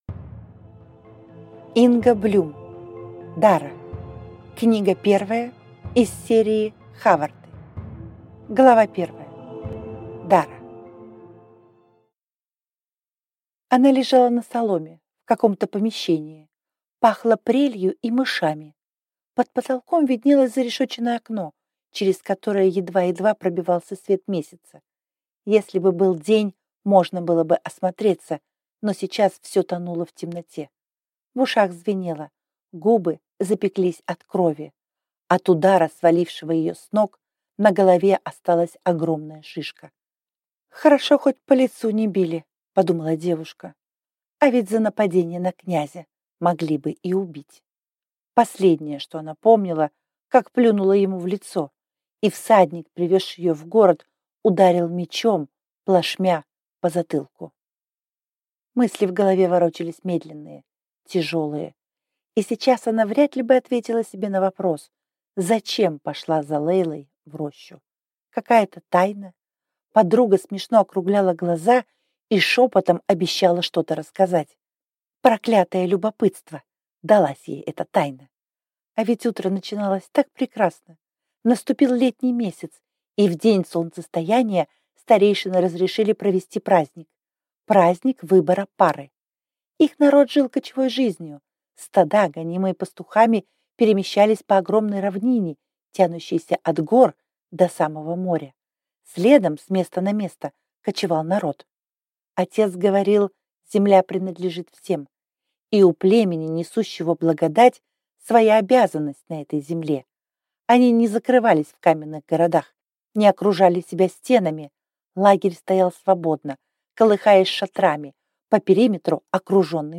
Аудиокнига Дара | Библиотека аудиокниг